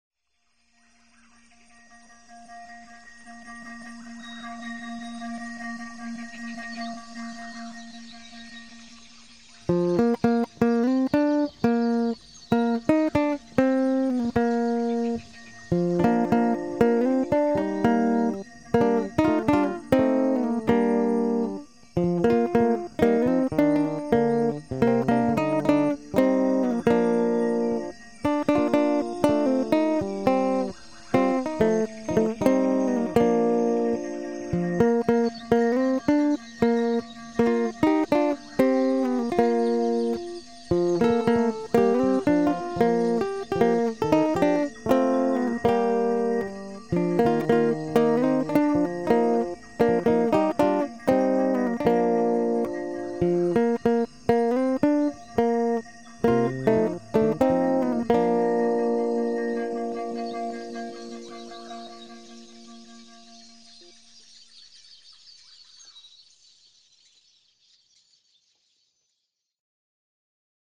Sintetizadores
Versiones Instrumentales